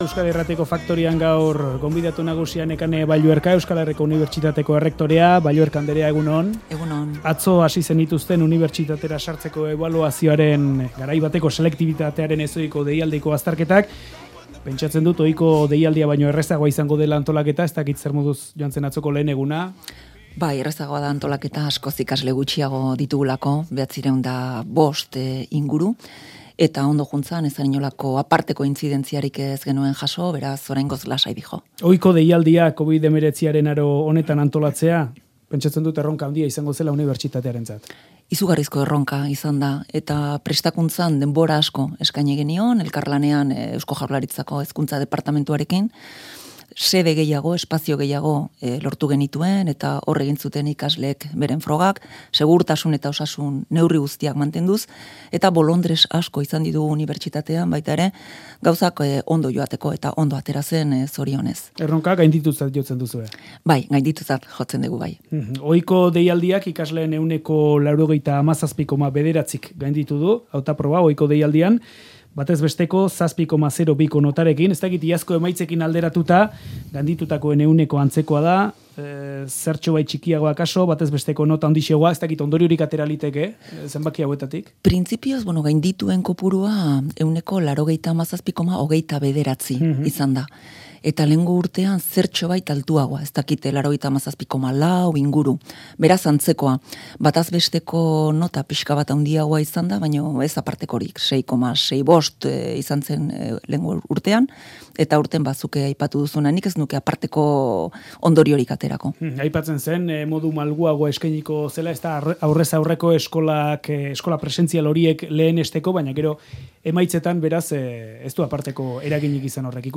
Audioa: “Faktoria”n izan da Euskal Herriko Unibertsitateko Erretore Nagusia.